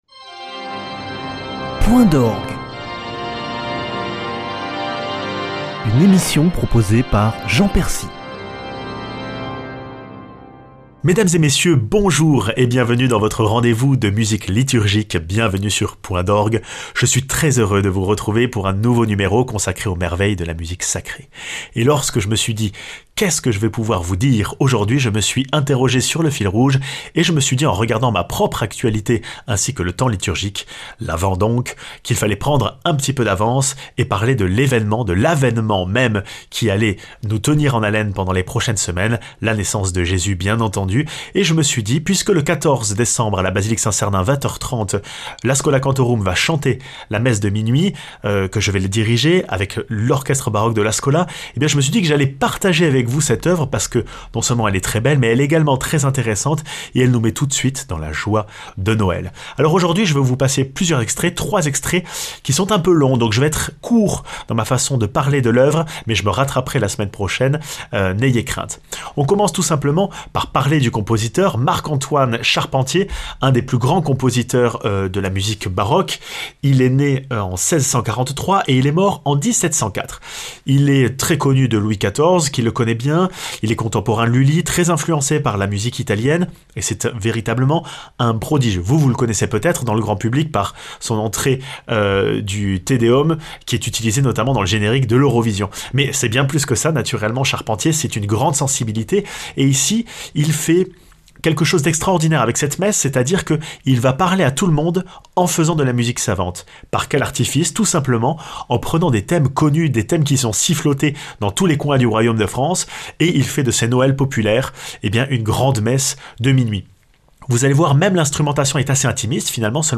Chef d'œuvre de l'art baroque, la messe de minuit de Charpentier fait partie des incontournables des concerts de l'avent.